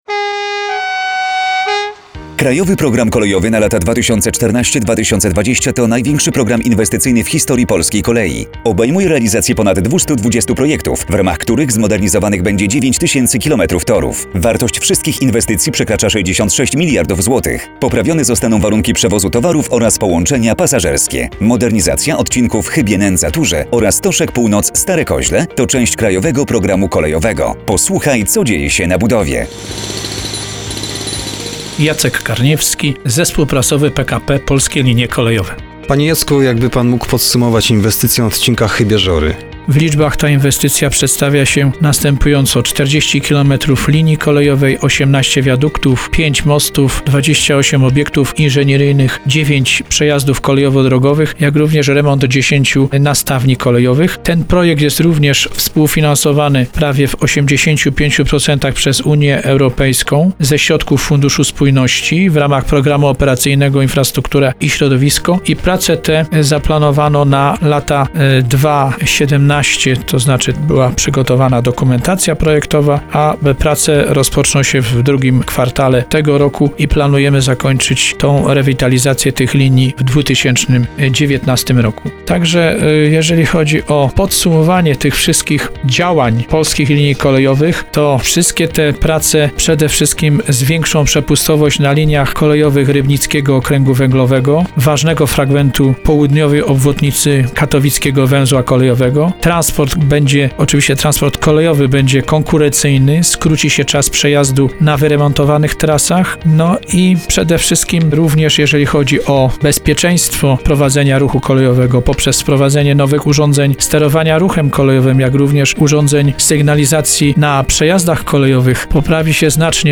Audycje radiowe - marzec 2018 r,, odc. 5/2